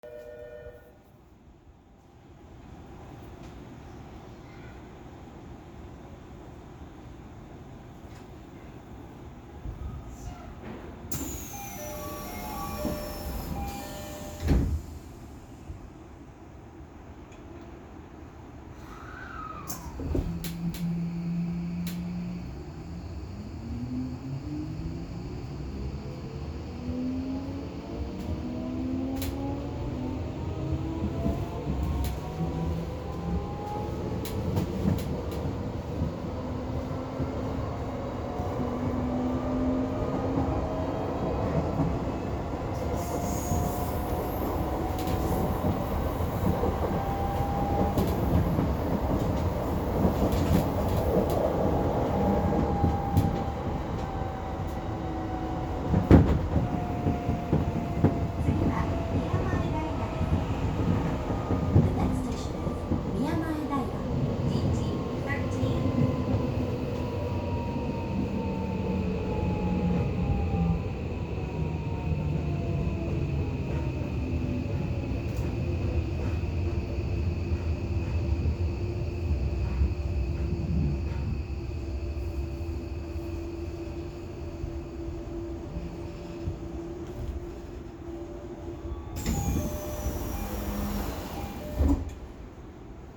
・18000系走行音
昨今のメトロにありがちな三菱フルSiC適用のPMSMとなっており、モーター音的にはパっとしない反面静粛性に非常に優れています。17000系の10両編成も基本的には同じ音です。